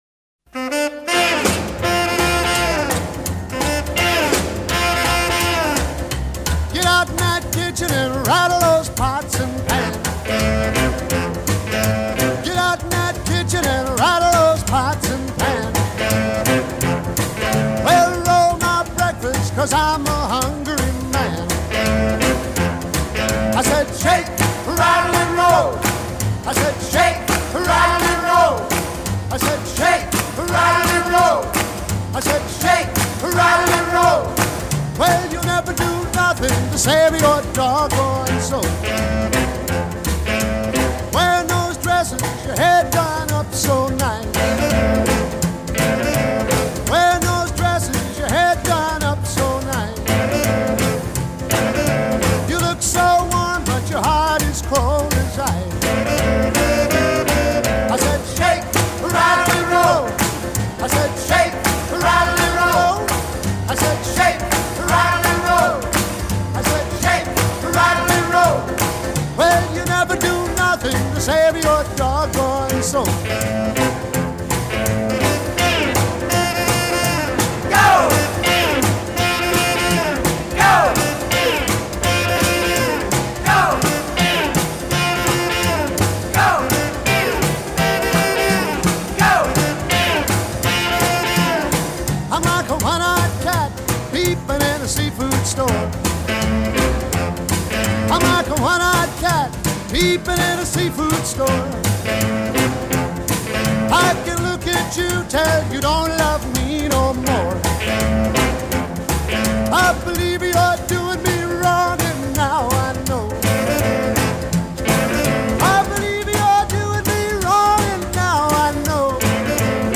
Lindy Hop Music
[Intro- 1 x 8 count around 7 seconds-no dancing but moving]